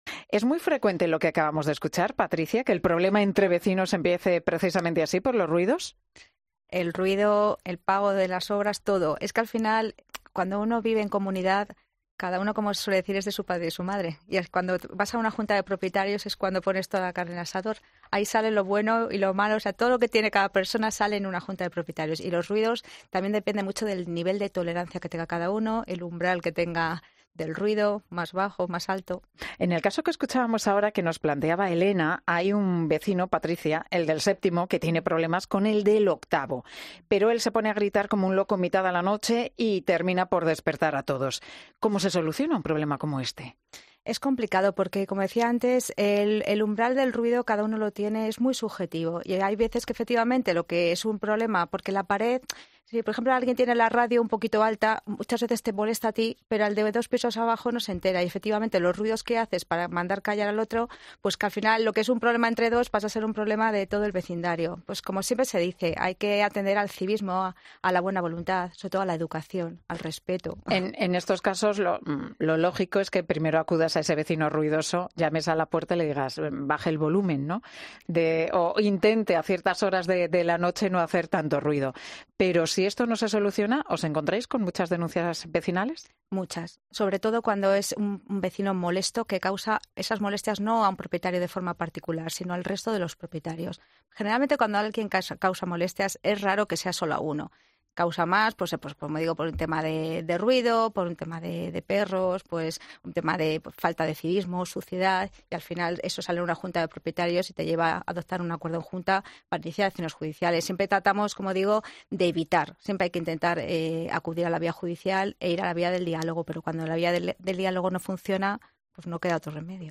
Una abogada experta desvela las graves consecuencias legales de los conflictos vecinales más comunes que pueden acabar en los tribunales